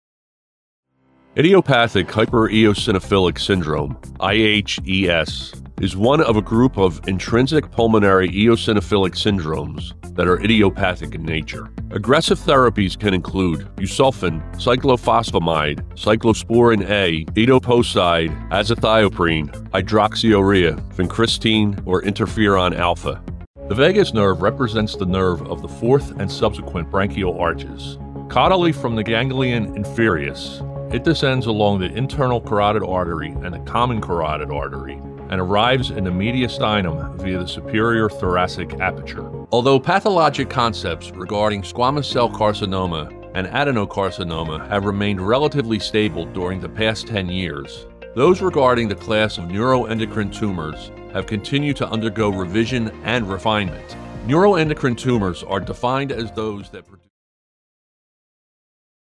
Never AI, always authentic.
Professional-grade equipment and acoustic treatment deliver broadcast-ready audio that rivals major market studios in New York and Los Angeles.
Medical Demo